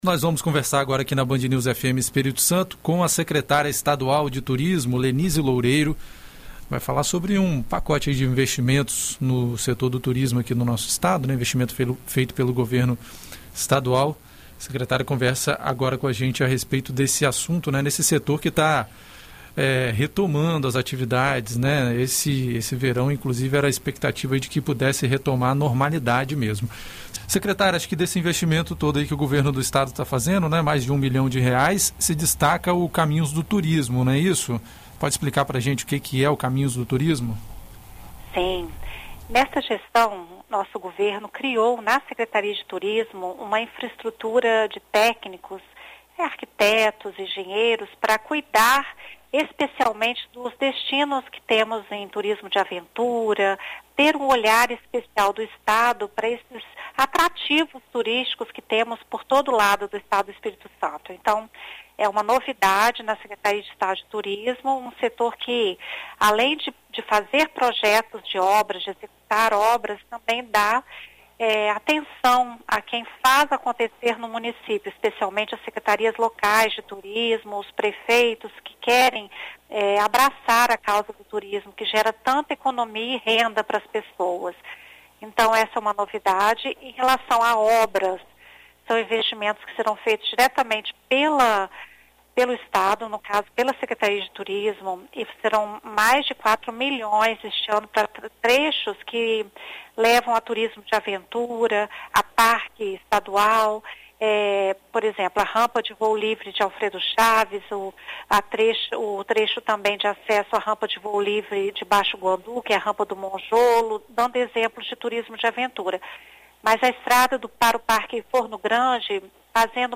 Em entrevista à BandNews FM, a Secretária Estadual de Turismo, Lenise Loureiro, comenta sobre as atividades previstas para este ano no setor